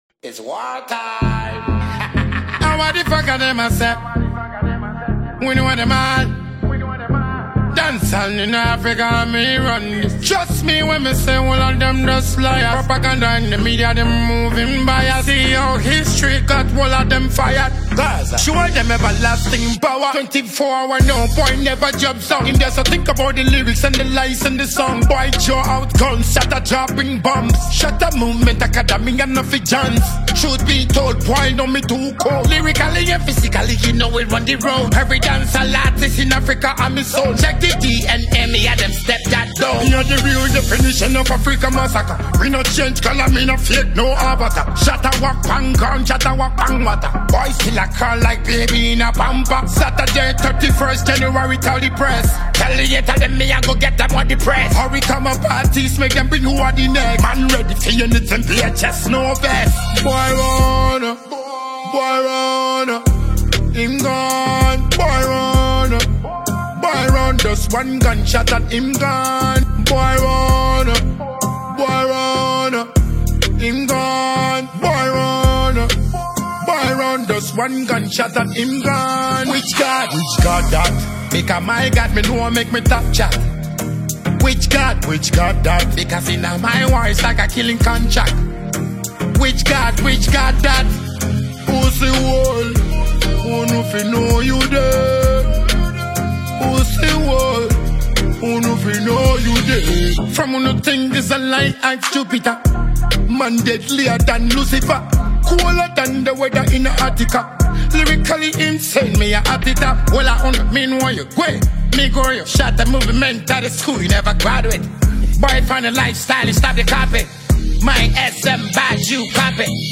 is an energetic dancehall anthem
Genre: Dancehall